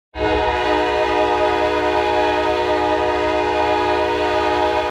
atlanta falcons horn Meme Sound Effect
atlanta falcons horn.mp3